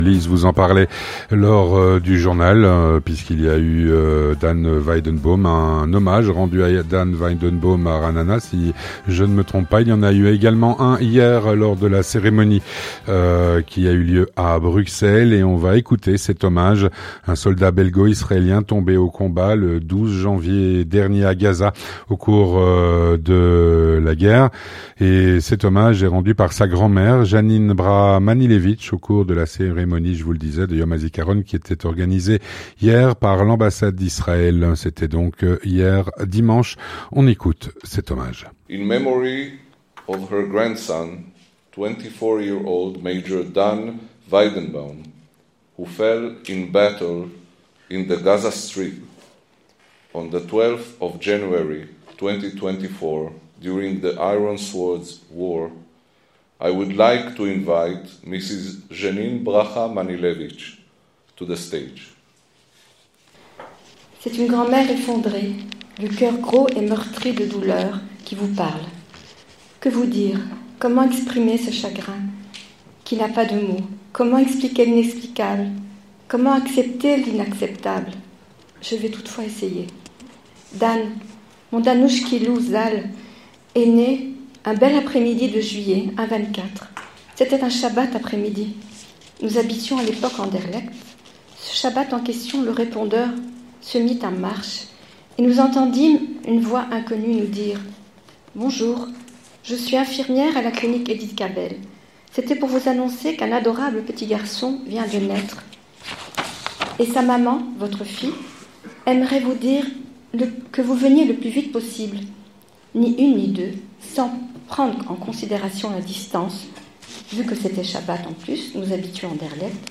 Un reportage effectué sur place